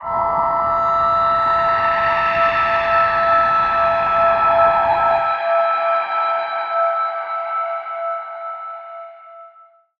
G_Crystal-E7-mf.wav